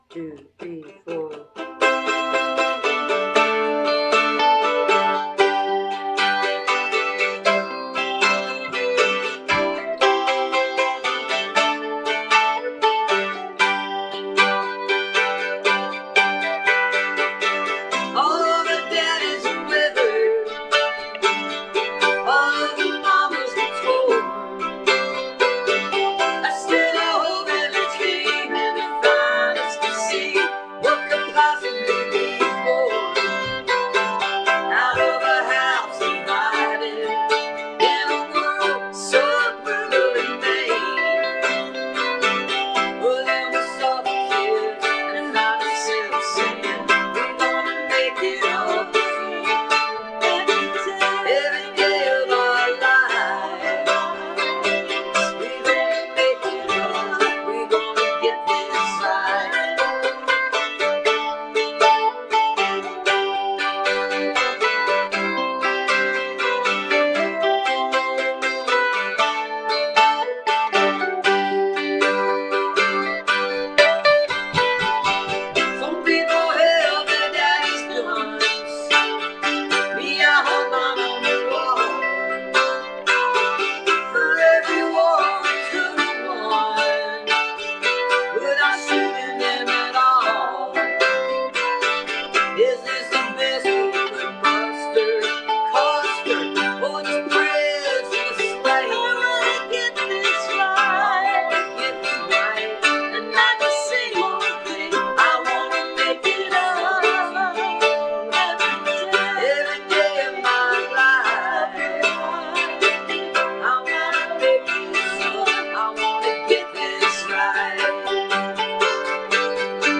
(captured from facebook)